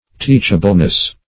Teachableness \Teach"a*ble*ness\, n.